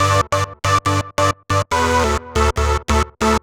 Index of /musicradar/future-rave-samples/140bpm
FR_JupeRaver_140-G.wav